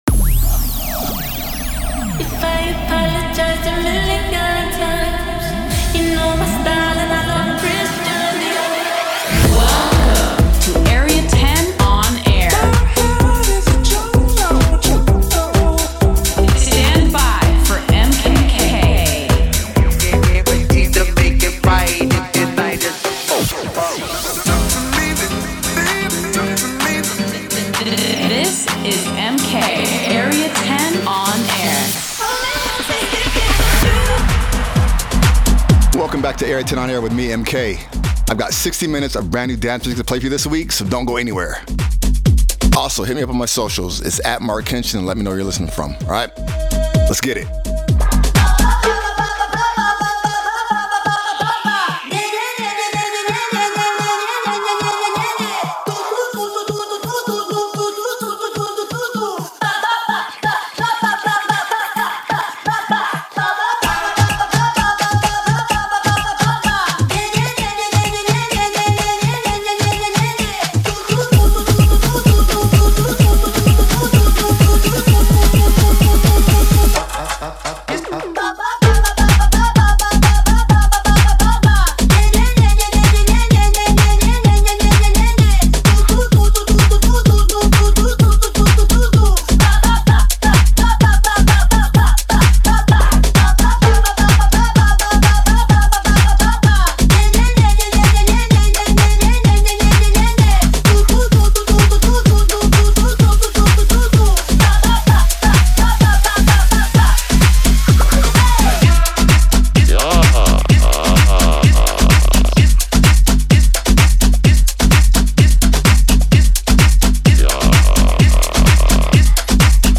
Delving in to house and techno